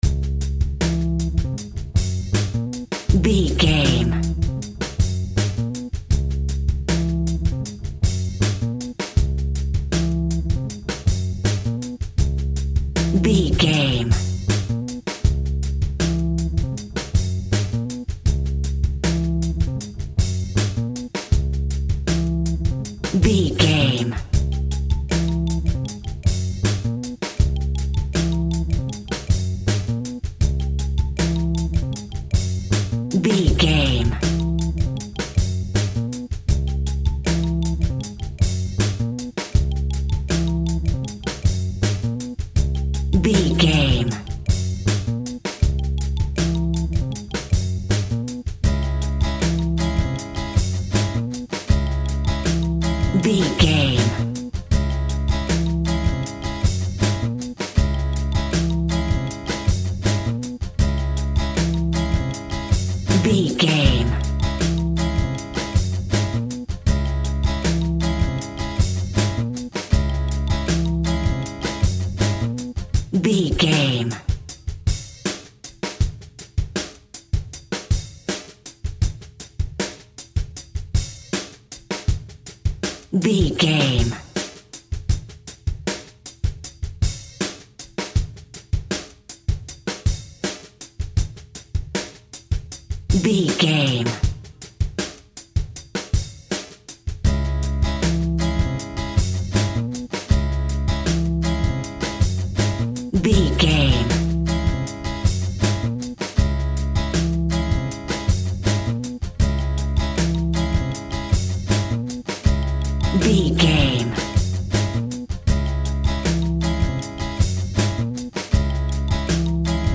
Hip Hop Tripping Music Cue.
Aeolian/Minor
C#
Funk
electronic
drum machine
synths